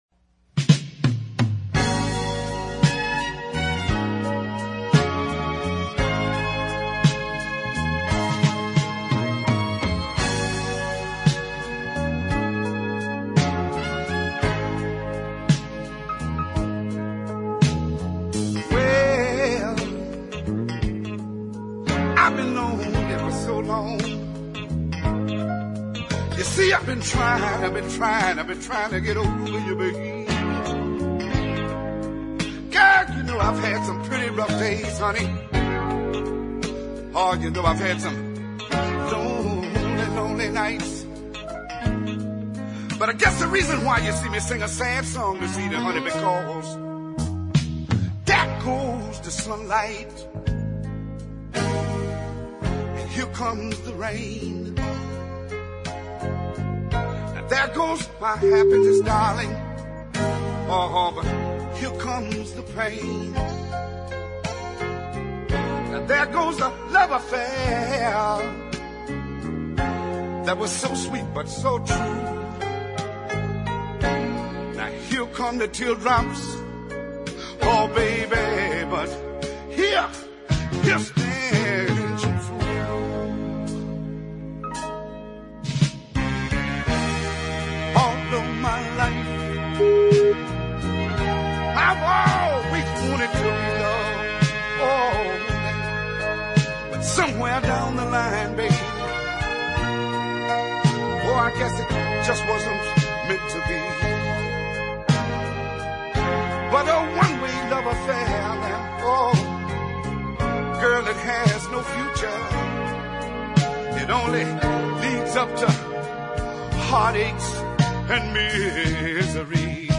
The southern soul sound of Listen
is a really strong ballad